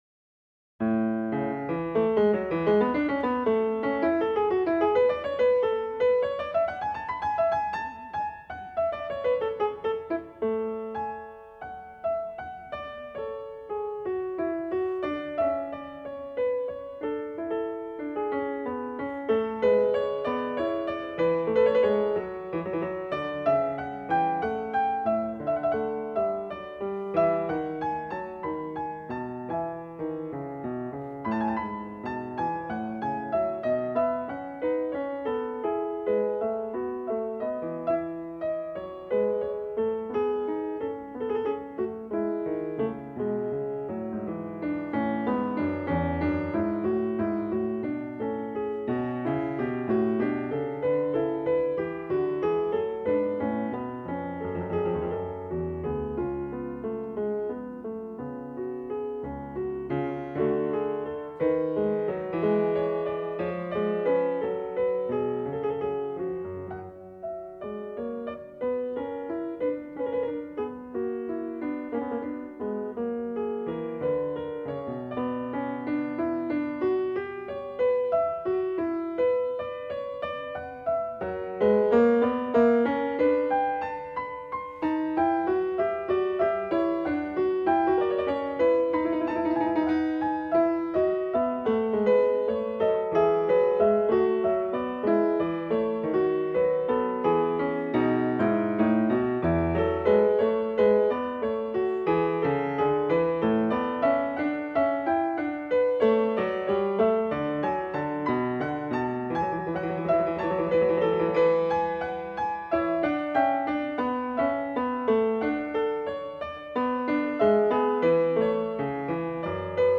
Overture in the French style